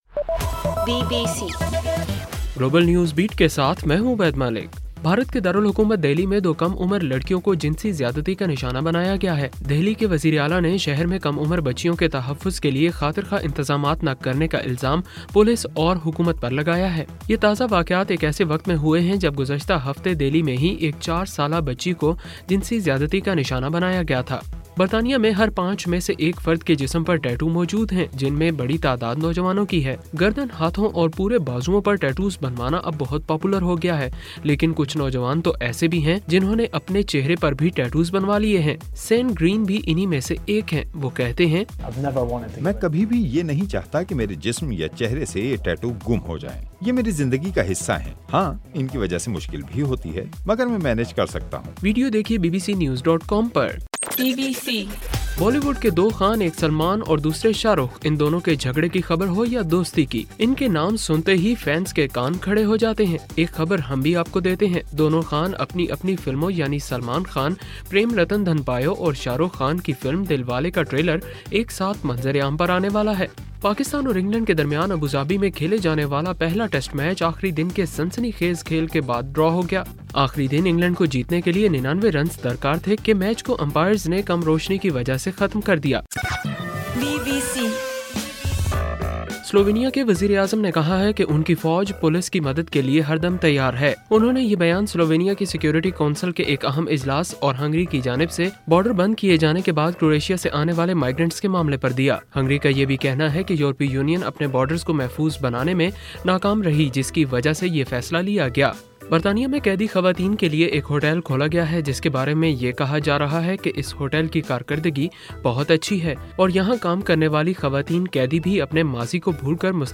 اکتوبر 17: رات 11 بجے کا گلوبل نیوز بیٹ بُلیٹن